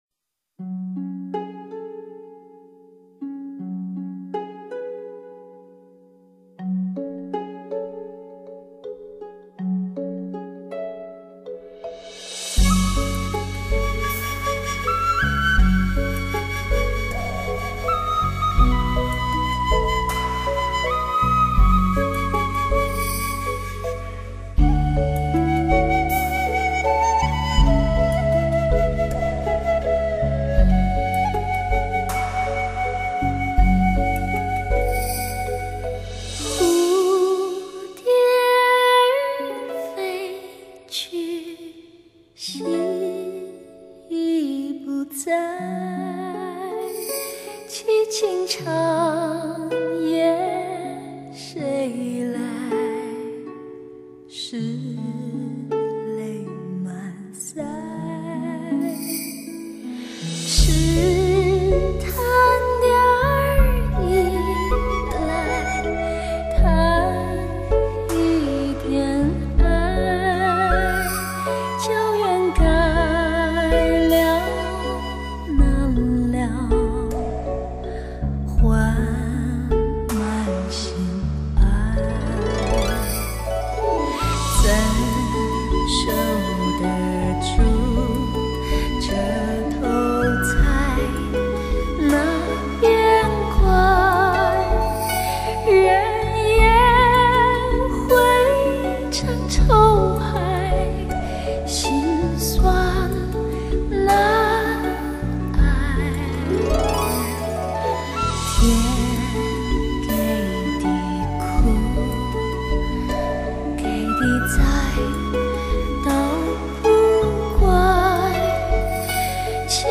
采用DSD录音技术，录音品质极佳。
配乐以清淡的钢琴和小提琴为主，非常考验唱功。